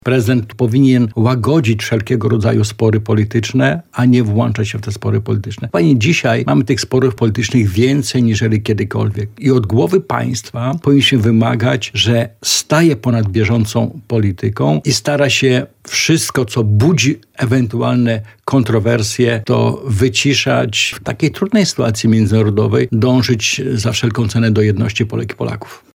Prezydent RP powinien zawsze reprezentować wszystkich Polaków, a nie tylko wybraną część obywateli – powiedział w porannej rozmowie Radia Lublin, poseł Platformy Obywatelskiej, Krzysztof Grabczuk.